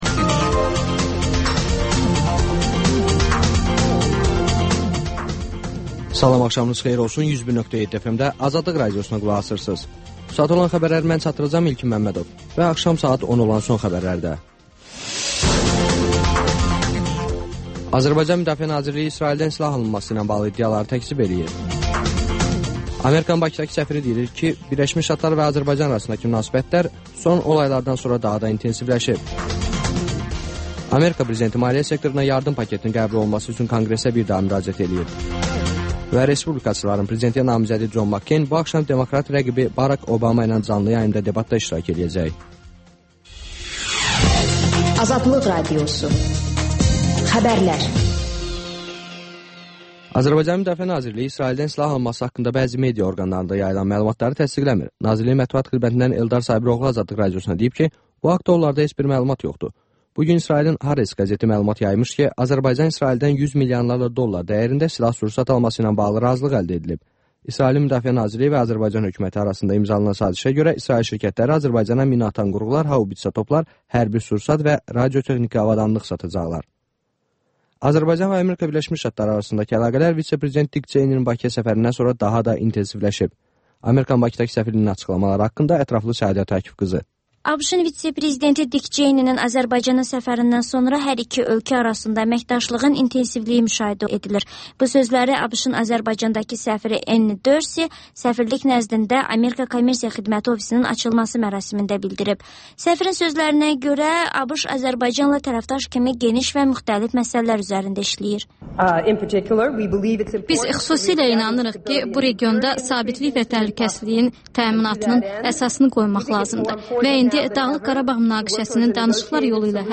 Xəbərlər, müsahibələr, hadisələrin müzakirəsi, təhlillər